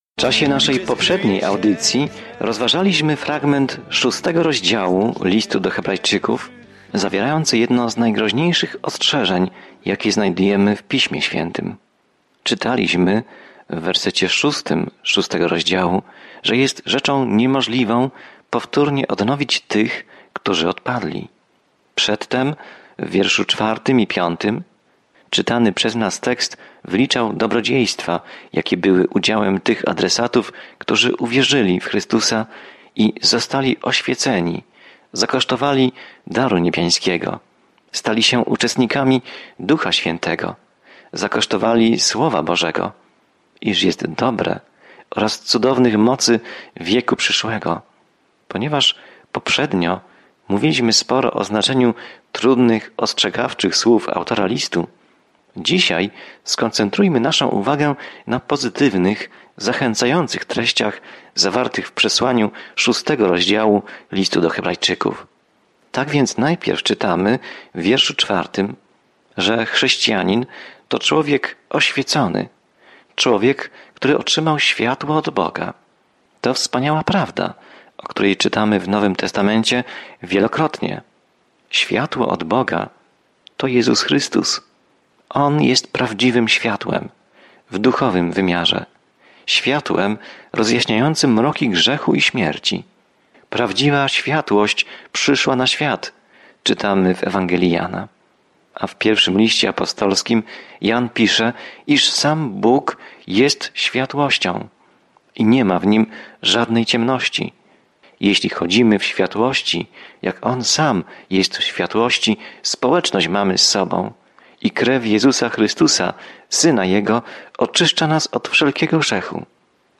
Codziennie podróżuj po Liście do Hebrajczyków, słuchając studium audio i czytając wybrane wersety słowa Bożego.